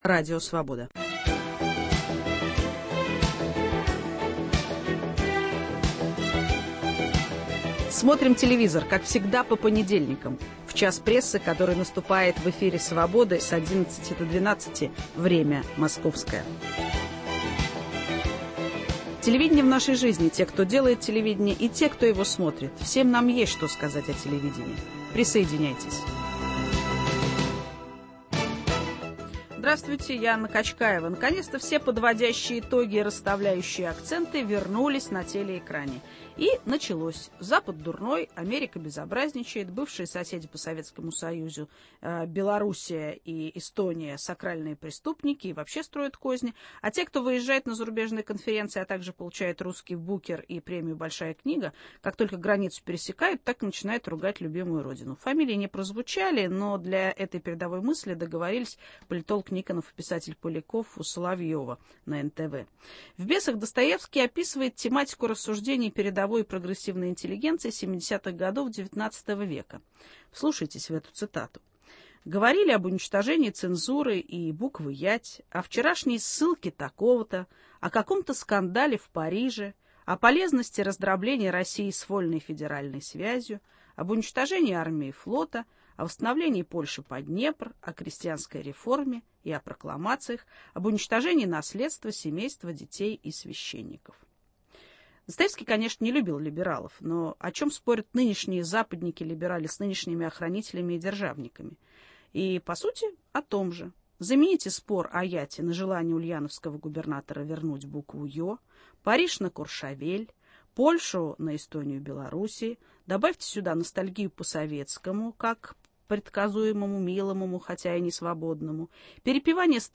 Что телевидение предлагает как рецепт национального самоутверждения, как интерпретирует тему исторической памяти и национальной гордости? В студии